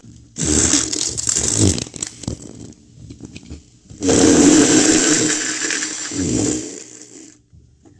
Знатная дристуха
toilet13.wav